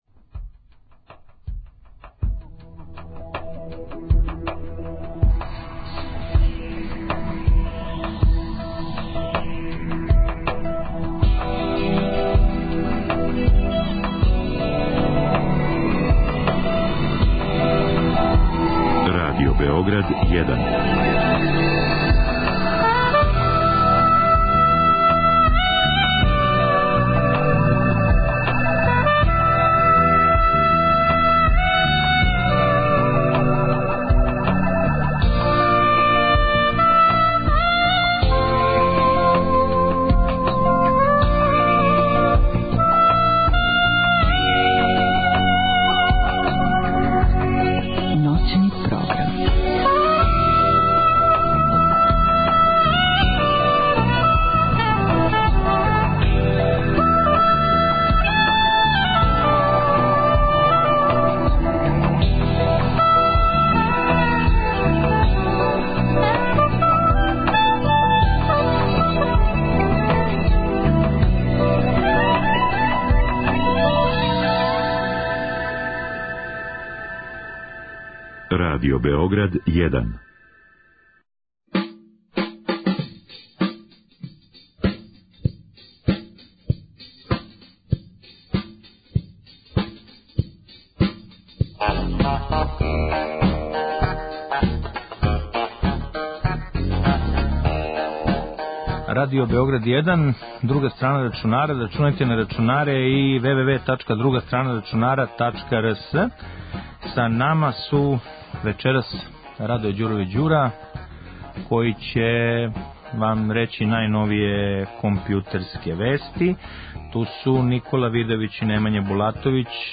Зa музички дeo пoбринућe сe групa Дингoспo Дaли.